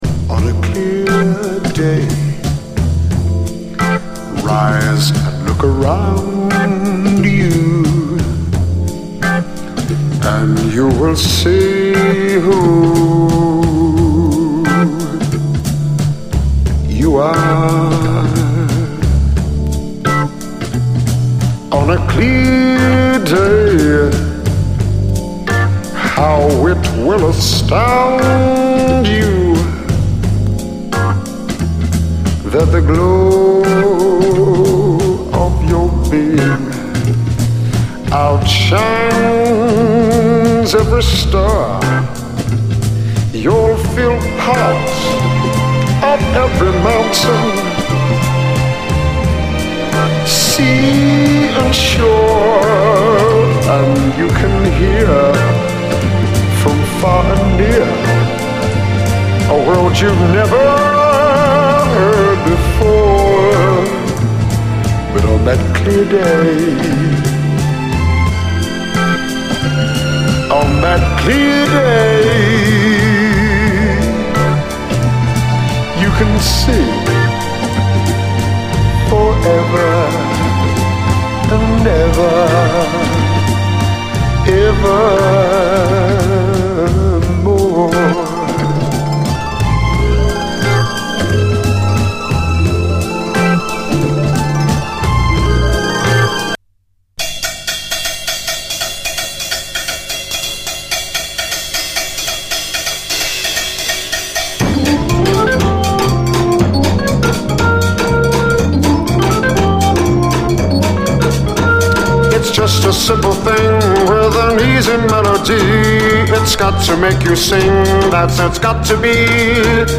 SOUL, 60's SOUL, JAZZ FUNK / SOUL JAZZ, JAZZ